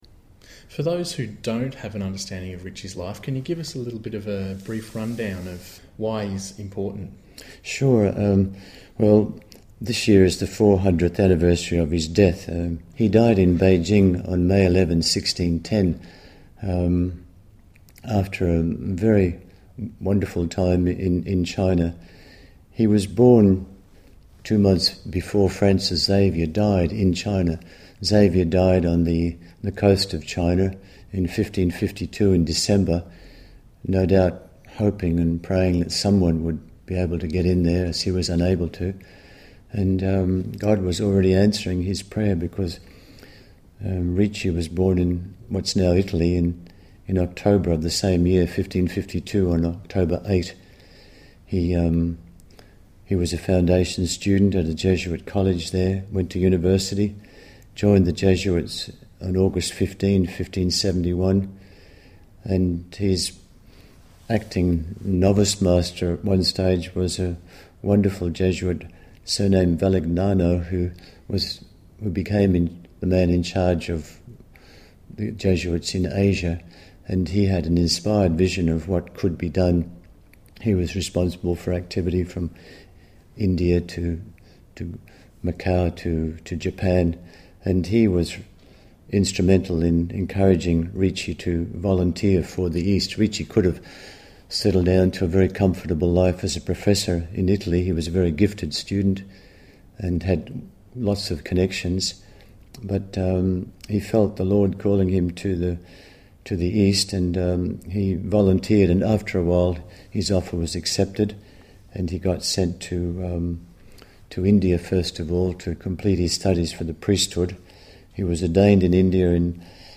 podcast interview
Ignatian_Conversations_April2010.mp3